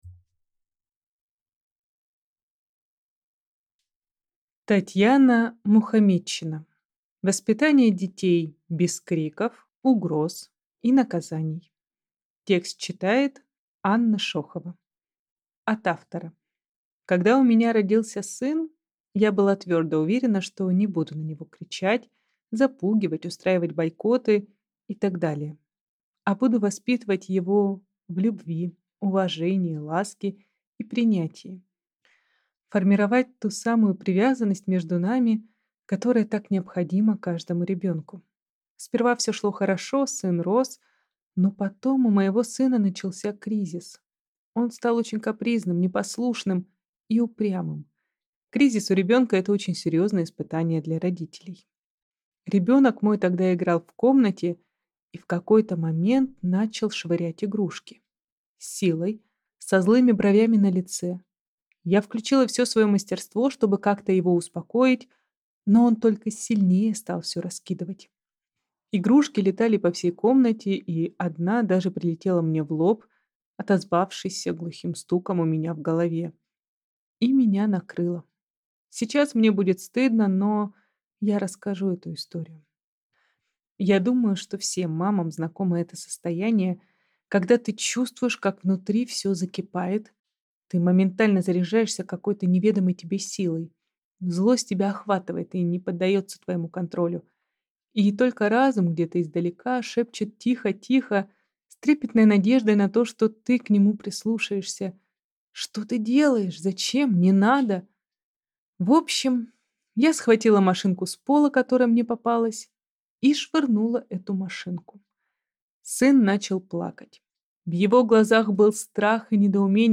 Аудиокнига Воспитание детей без криков, угроз и наказаний | Библиотека аудиокниг